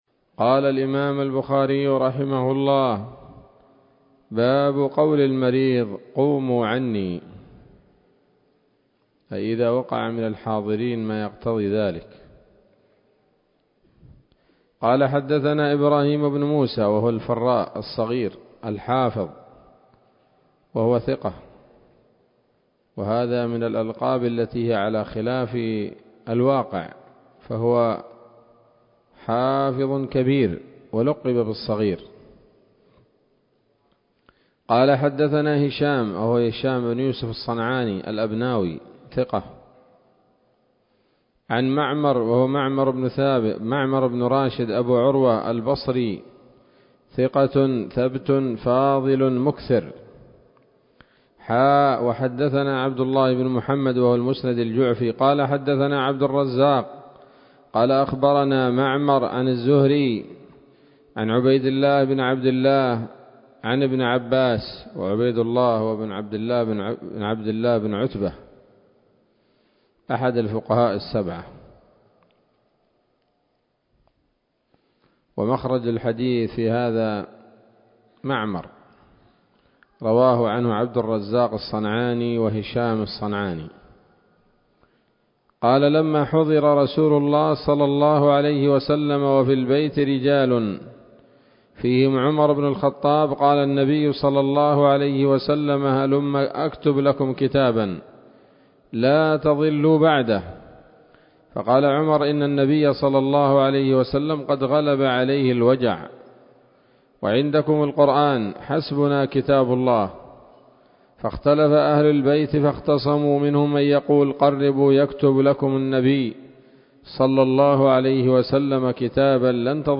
الدرس الثالث عشر من كتاب المرضى من صحيح الإمام البخاري